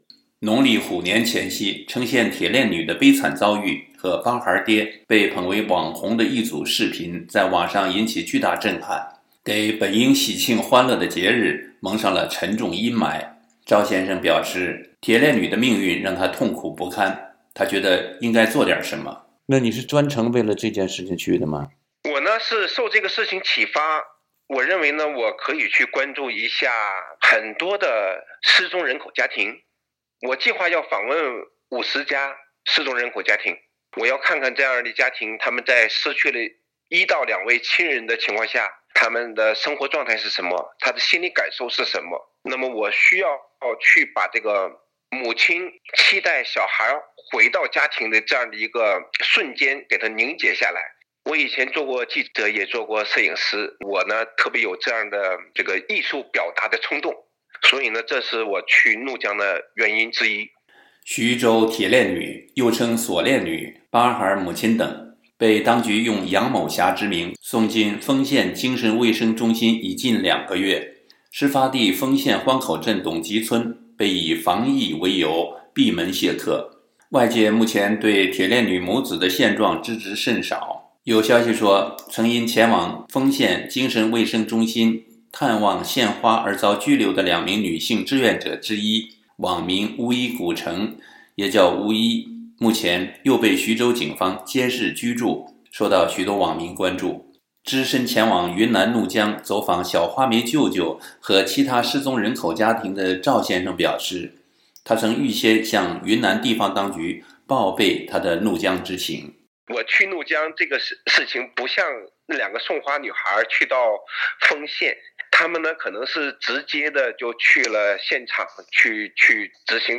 专访关爱失踪人口家庭志愿者（2）：充满困惑的怒江惊魂之旅